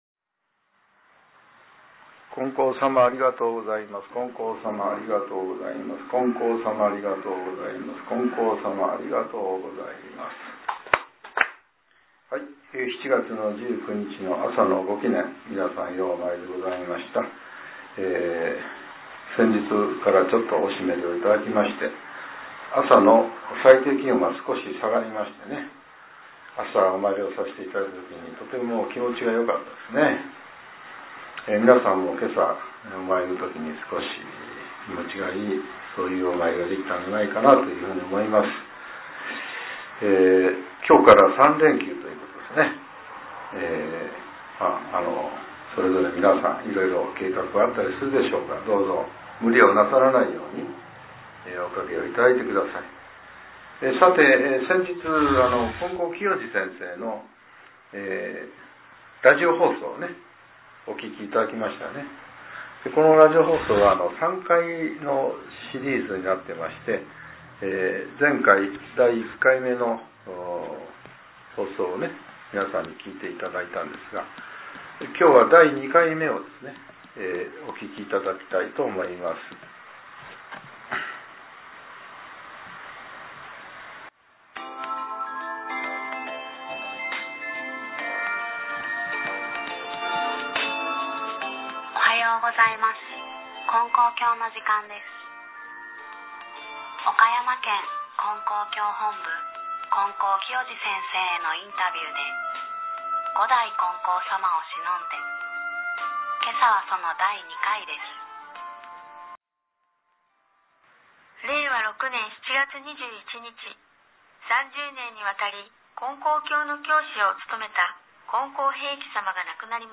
令和７年７月１９日（朝）のお話が、音声ブログとして更新させれています。